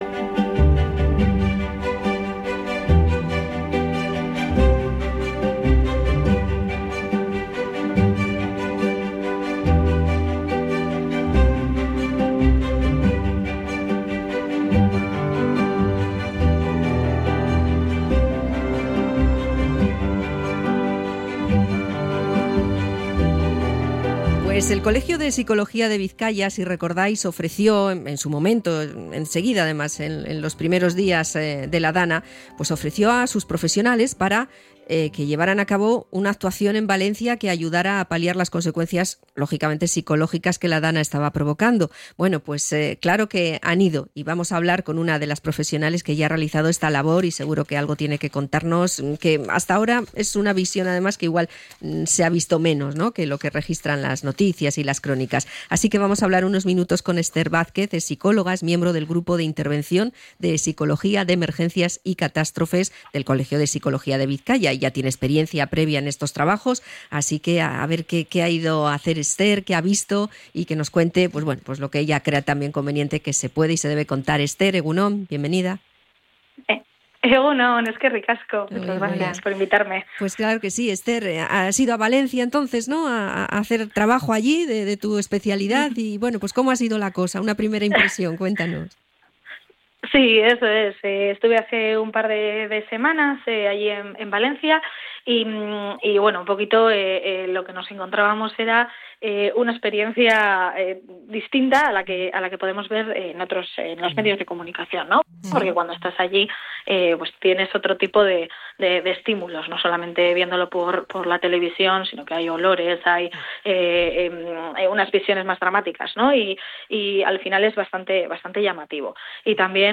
Entrevista a psicóloga por la intervención psicológica tras la DANA